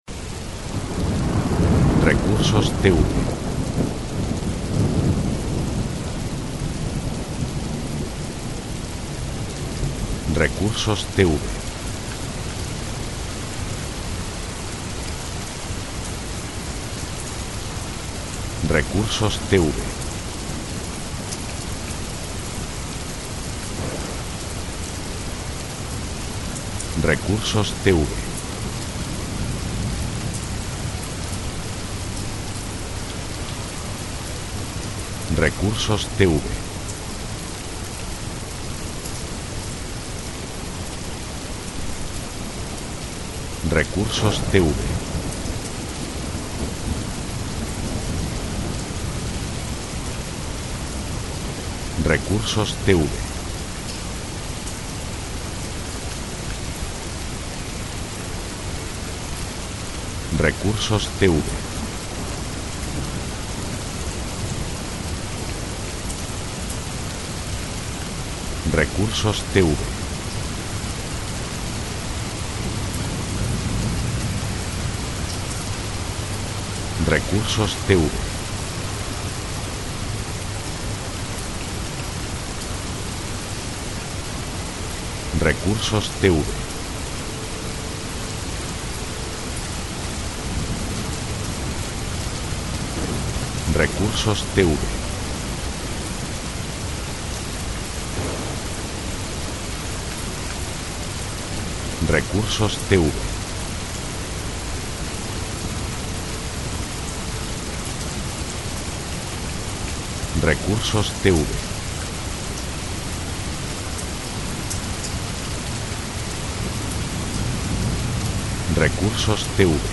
Efecto de lluvia intensa con truenos.